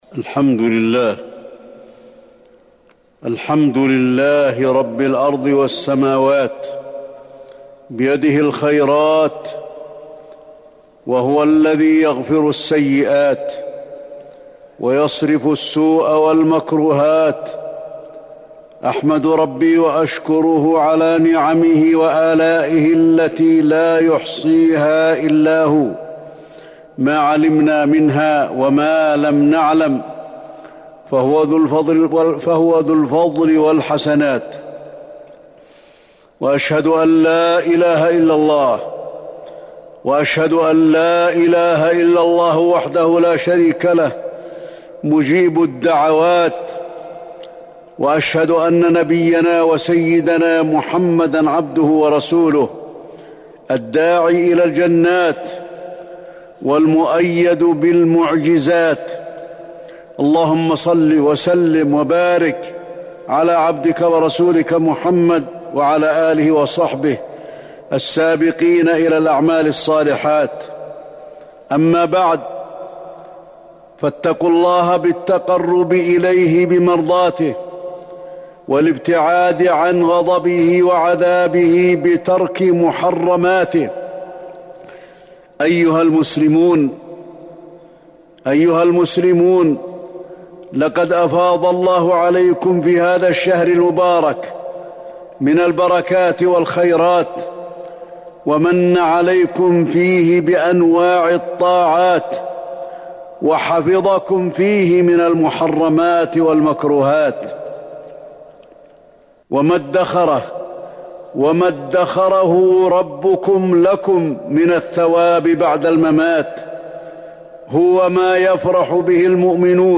تاريخ النشر ٢٢ رمضان ١٤٤١ هـ المكان: المسجد النبوي الشيخ: فضيلة الشيخ د. علي بن عبدالرحمن الحذيفي فضيلة الشيخ د. علي بن عبدالرحمن الحذيفي الأوقات الشريفة هي زمان الأعمال